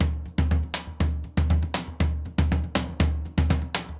Surdo Baion 120_2.wav